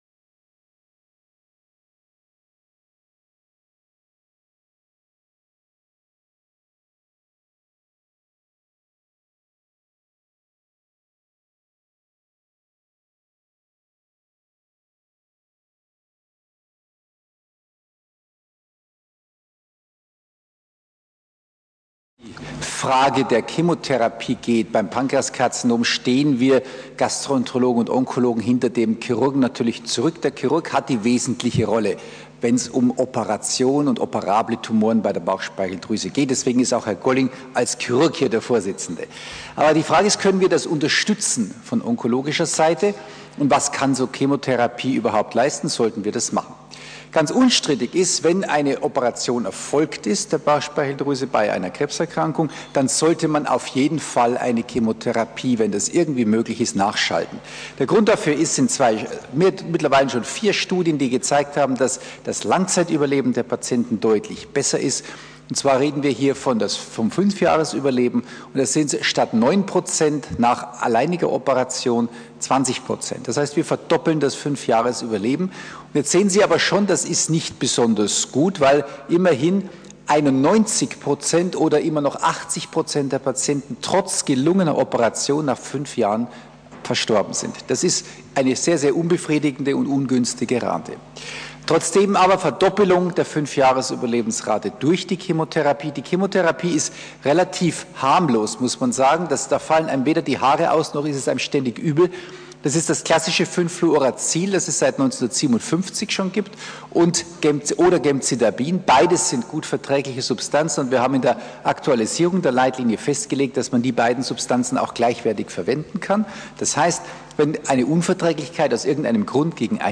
Vortrag 4 web.mp3